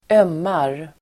Uttal: [²'öm:ar]